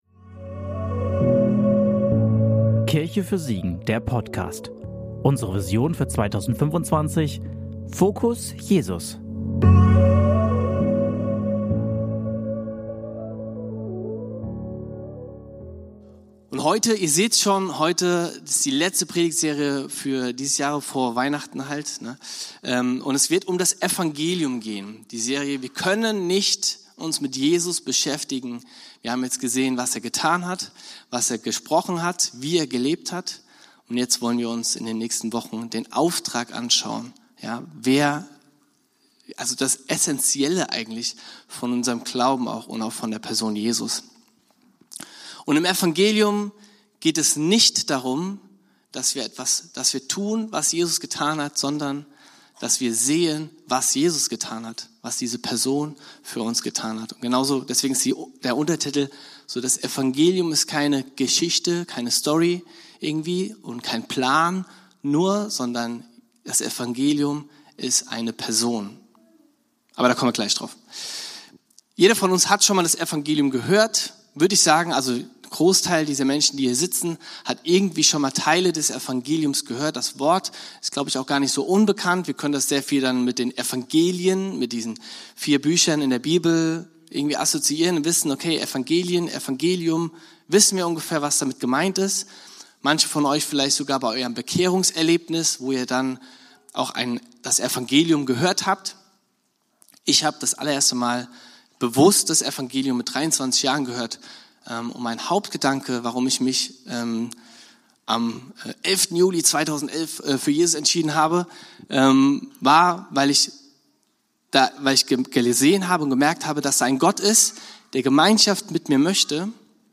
Predigt vom 12.10.2025 in der Kirche für Siegen